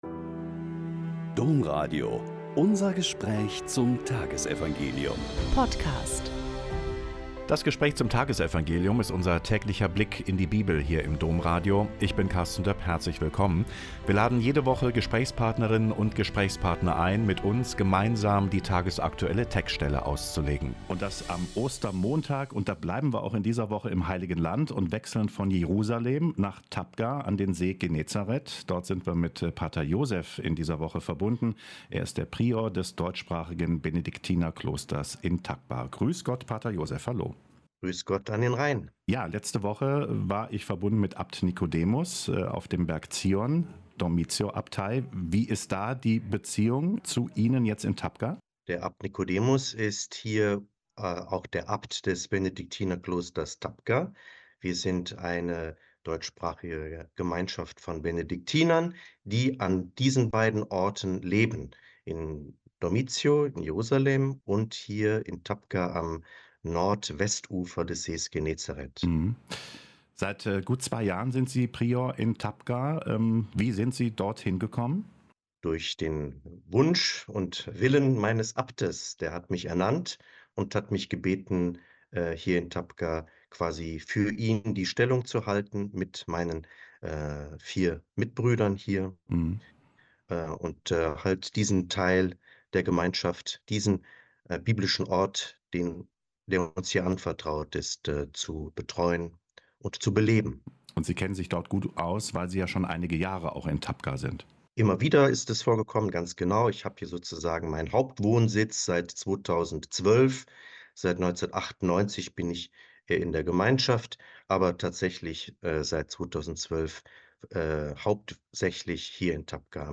Lk 24,13-35 - Gespräch